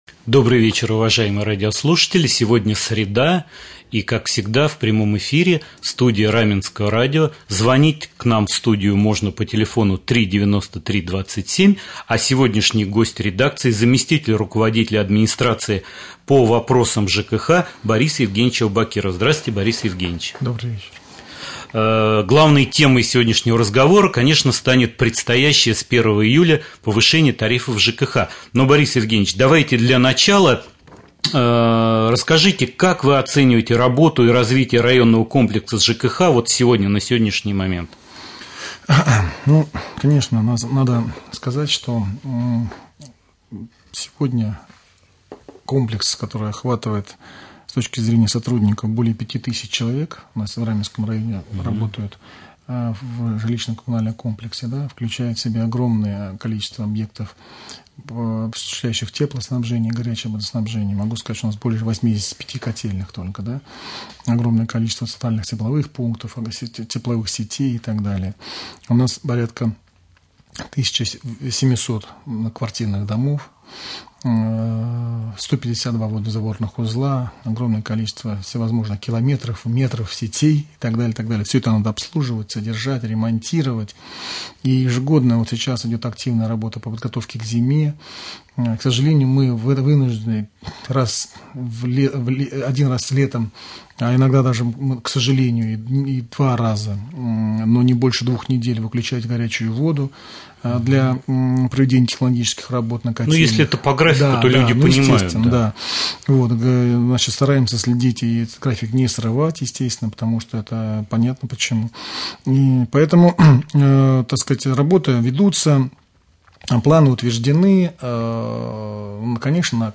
Гость студии Борис Евгеньевич Аубакиров заместитель руководителя администрации Раменского муниципального района по вопросам ЖКХ.